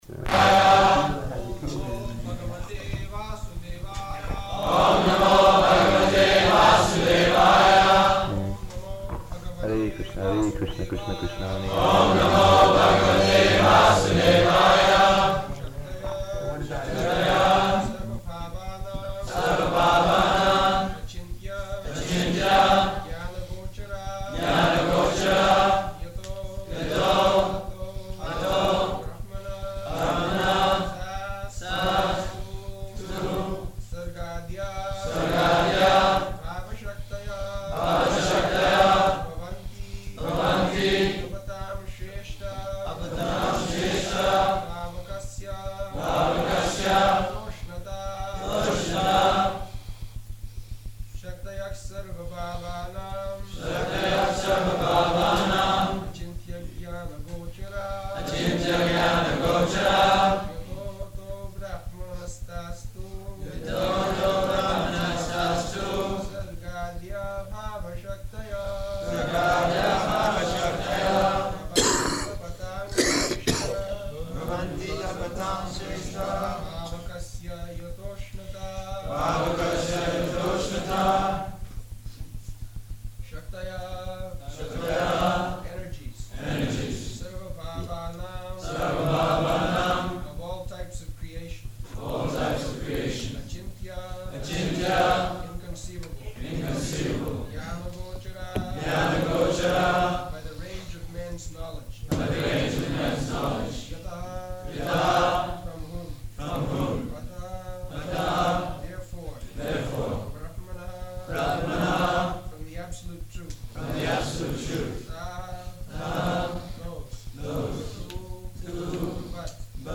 July 23rd 1976 Location: London Audio file
[devotees repeat] [chants verse, etc.] śaktayaḥ sarva-bhāvānām acintya-jñāna-gocarāḥ yato 'to brahmaṇas tās tu sargādyā bhāva-śaktayaḥ bhavanti taptatāṁ śreṣṭha pāvakasya yathoṣṇatā [ Cc.